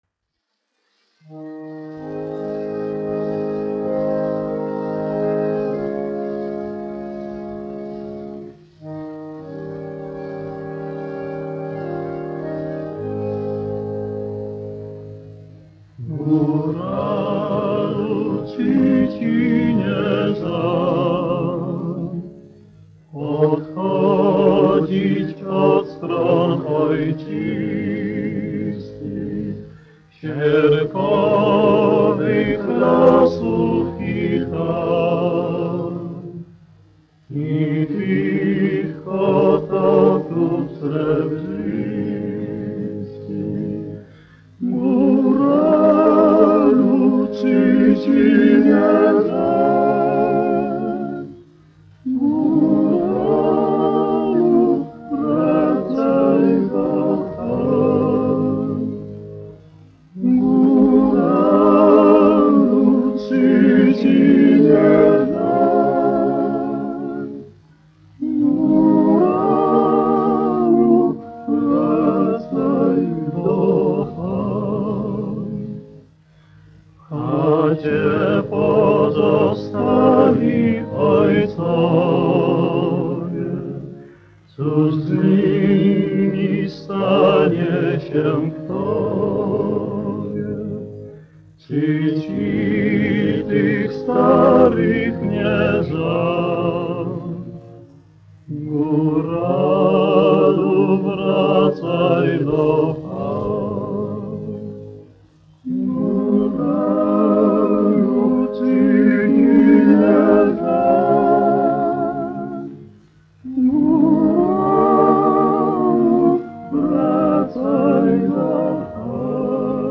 1 skpl. : analogs, 78 apgr/min, mono ; 25 cm
Poļu tautasdziesmas
Vokālie kvarteti
Latvijas vēsturiskie šellaka skaņuplašu ieraksti (Kolekcija)